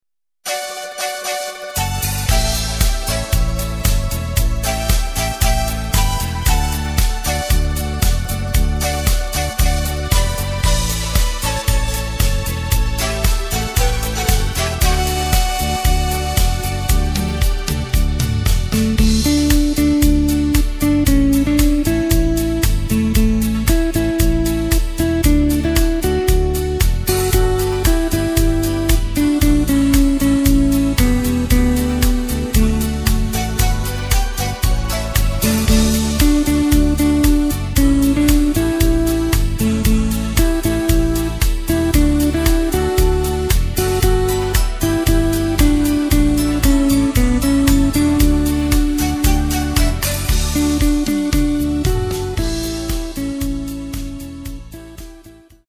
Takt:          4/4
Tempo:         115.00
Tonart:            D
Schlager aus dem Jahr 1996!